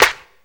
Ug_Clp.wav